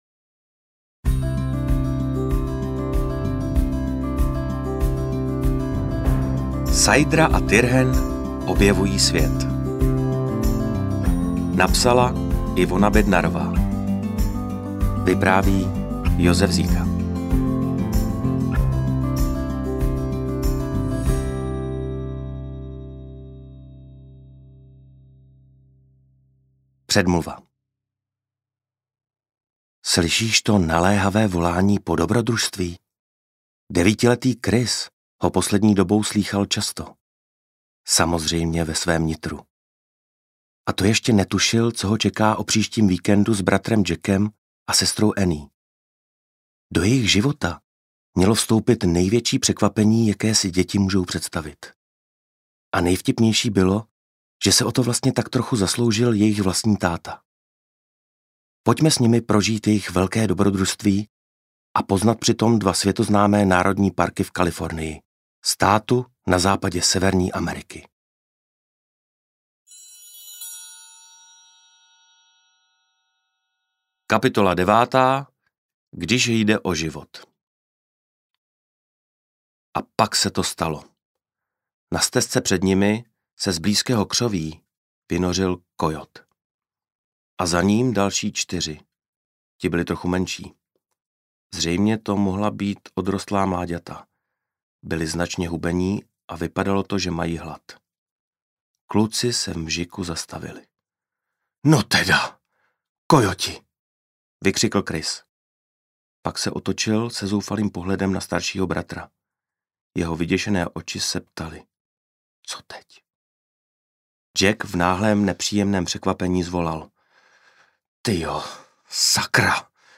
Sajdra a Tyrhen objevují svět audiokniha
Ukázka z knihy
sajdra-a-tyrhen-objevuji-svet-audiokniha